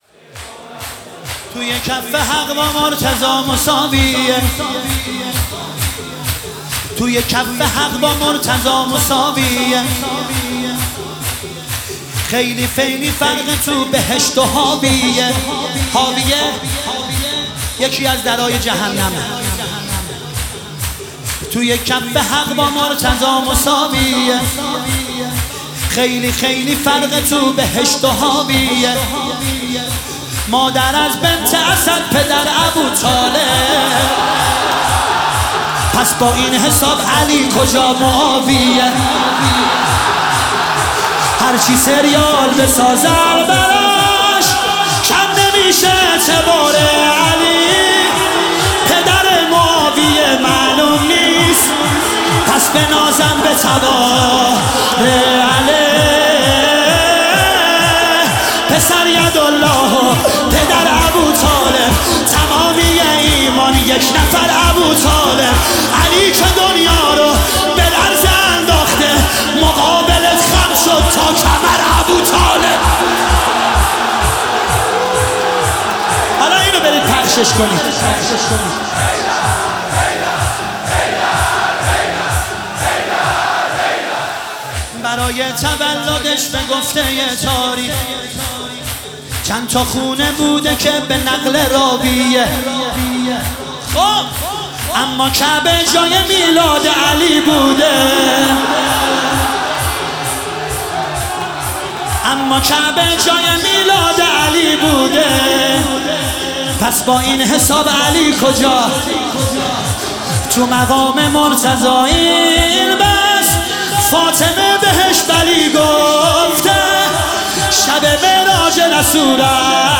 1 0 توی کف حق با مرتضی مساویه - دانلود صوت مداحی شور حضرت امیرالمومنین علی